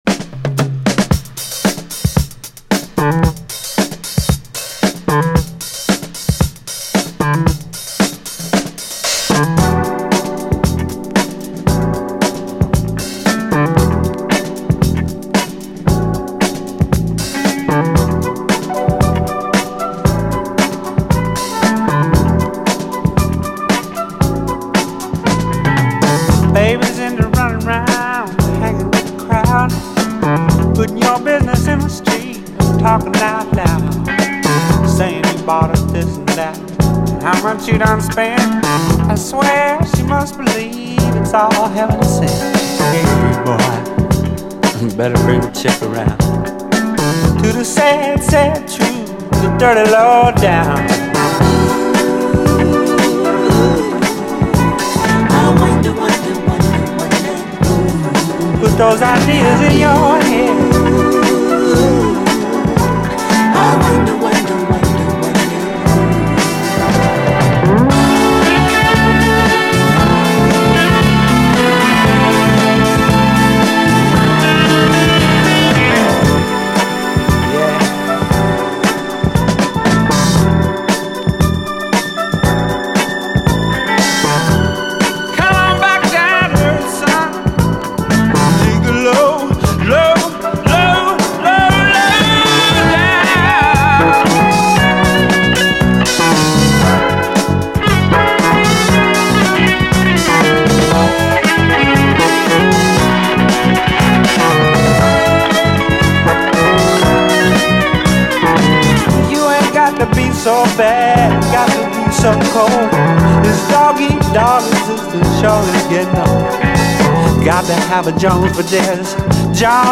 SOUL, 70's～ SOUL, SSW / AOR, ROCK, 7INCH
ドラム・ブレイク、そして続く特徴的なベース・ラインでお馴染みの70’Sソウル・クラシック！